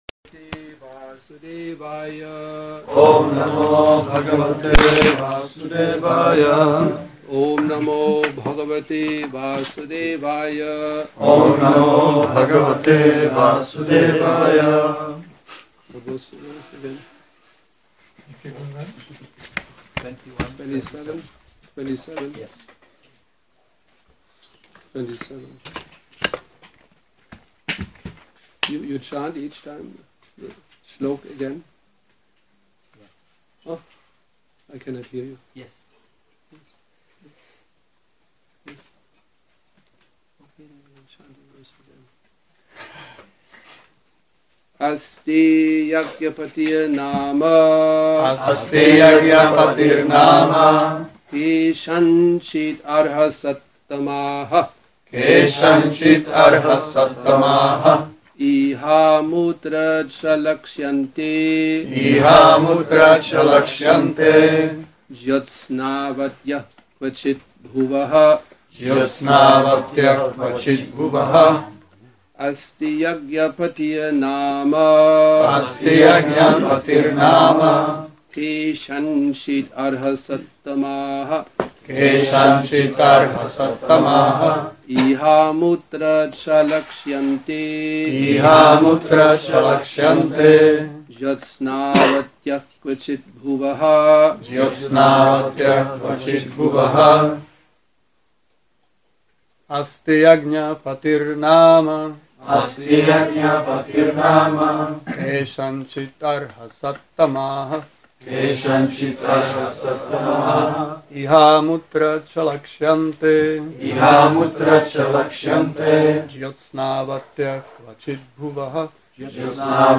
Přednáška SB-4.21.27 Continue